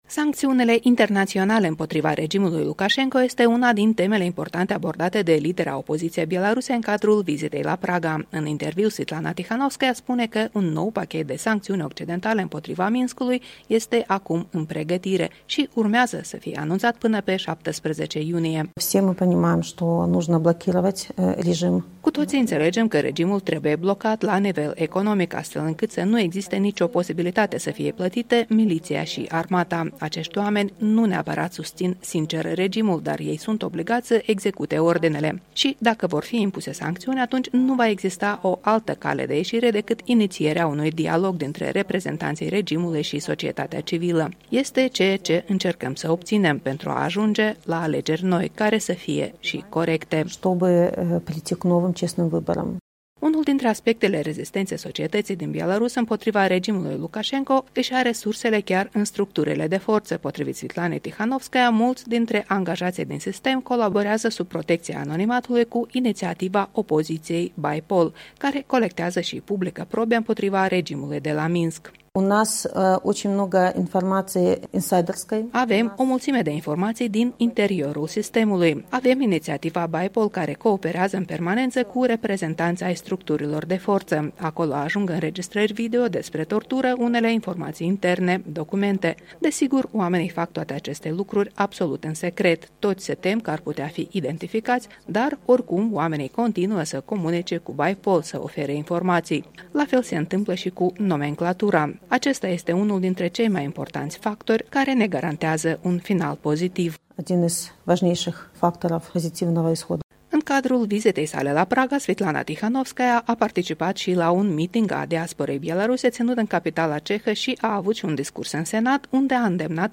Își încheie o vizită de trei zile la Praga lidera exilată a opoziției belaruse, Svetlana Tihanovskaia, care ne-a vizitat la sediul Europei Libere, acordând și un interviu televiziunii Current Time.